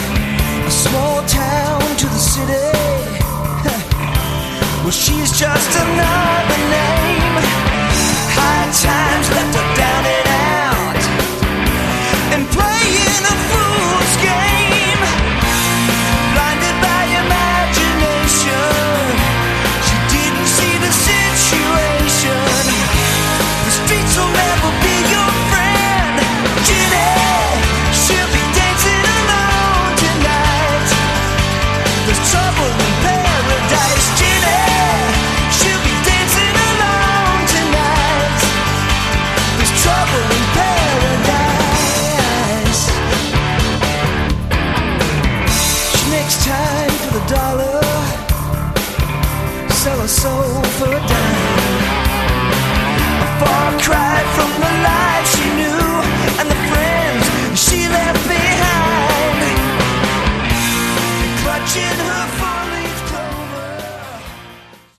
Category: AOR
vocals, guitars
bass, keyboards
drums
piano
backing vocals